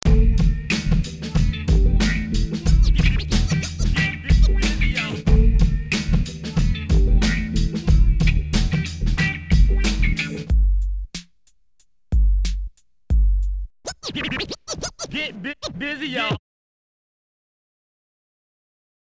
Sound Factory: RAP